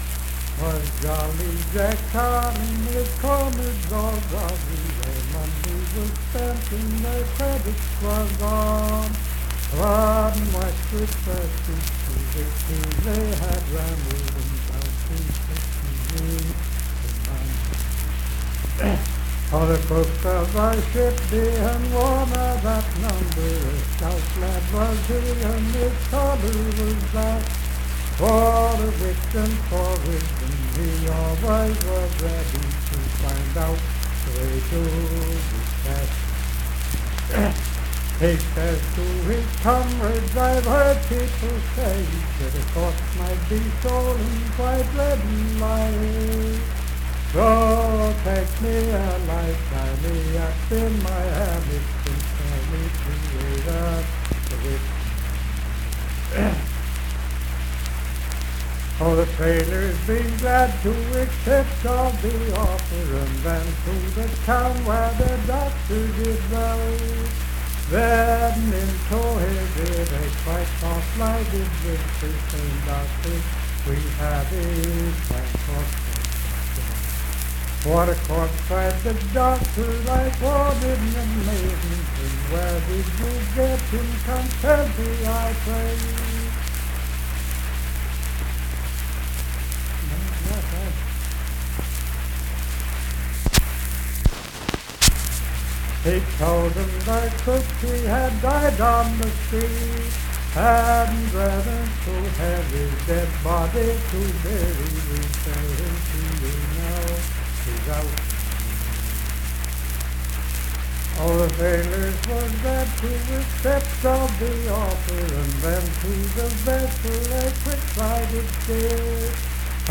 Unaccompanied vocal music
Richwood, Nicholas County, WV.
Voice (sung)